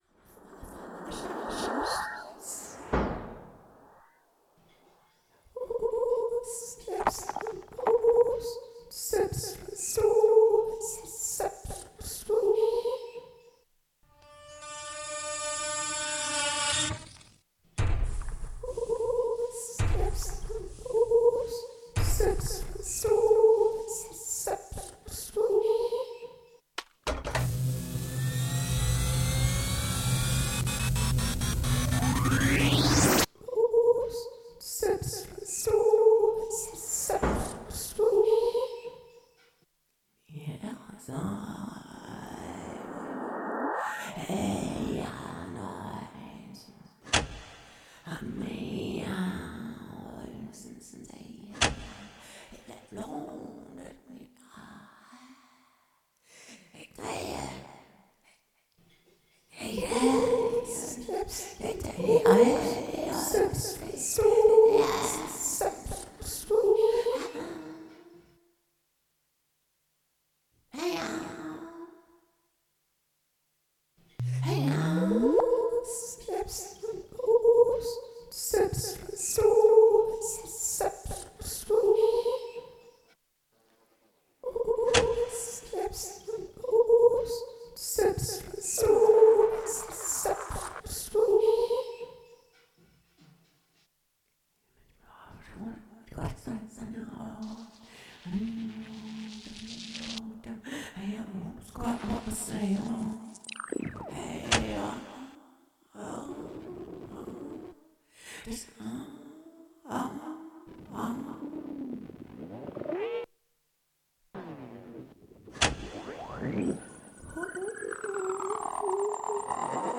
um sich intensiv mit musikalischen gemeinsamkeiten auseinanderzusetzen und schliesslich stimme und elektronik auf ähnlichen ebenen erklingen zu lassen.
demos: